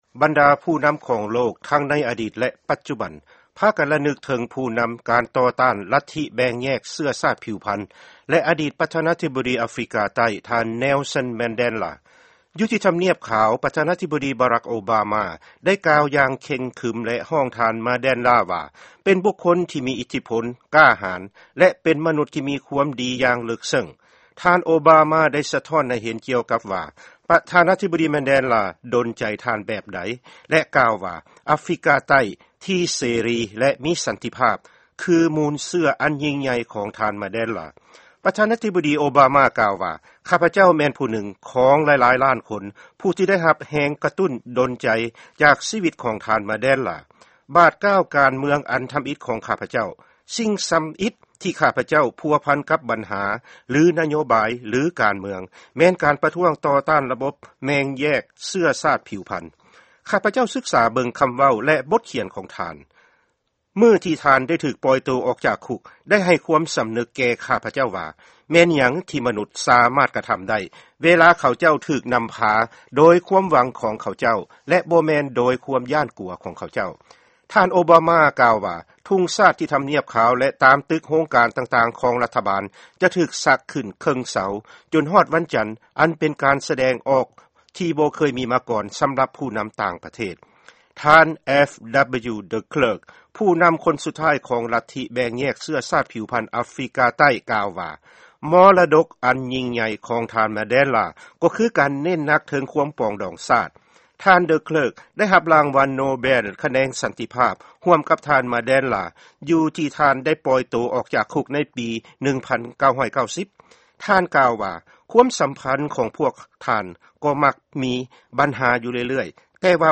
ຟັງຂ່າວ ການມໍລະນະກໍາຂອງ ທ່ານ Nelson Mandela